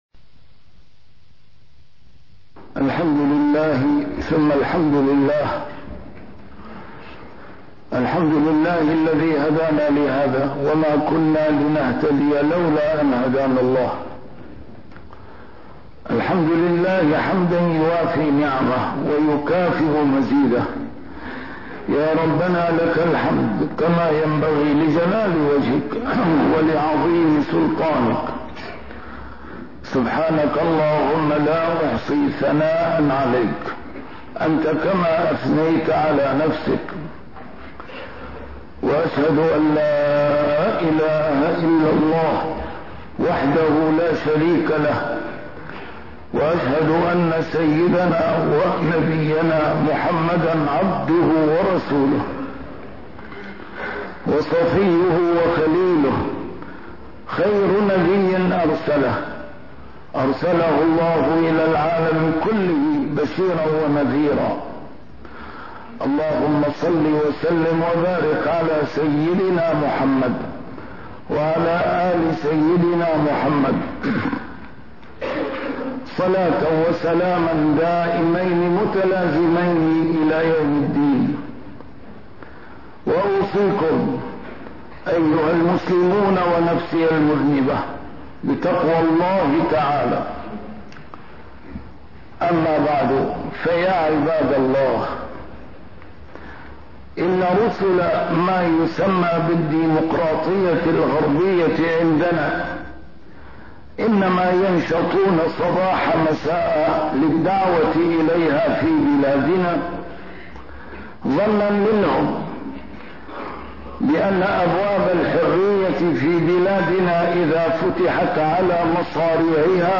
A MARTYR SCHOLAR: IMAM MUHAMMAD SAEED RAMADAN AL-BOUTI - الخطب - عندما تستخدم الديمقراطية لمصادرة الحرية